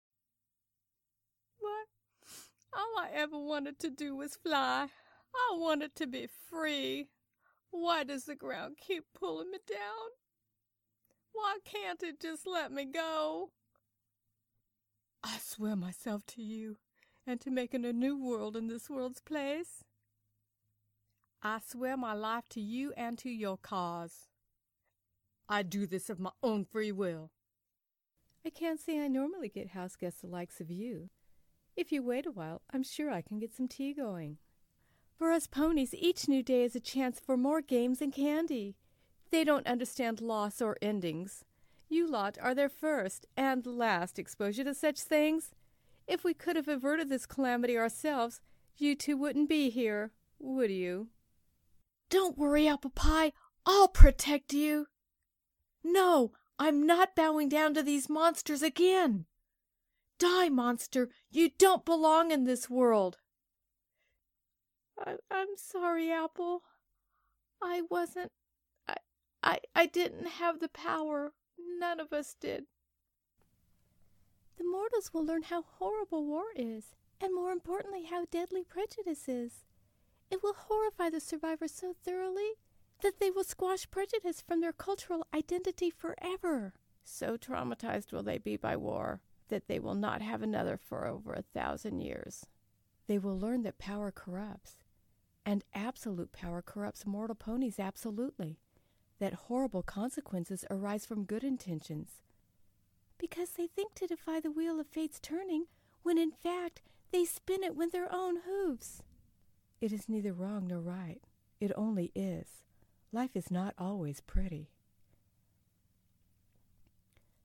Different Voices that I voiced during this voice-over project.